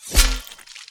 melee-hit-11.mp3